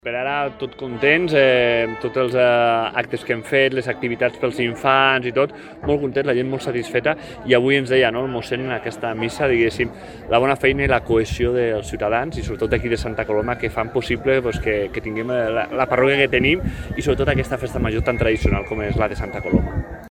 Gonzàlez ha fet aquestes declaracions en el marc de la festa major de Santa Coloma.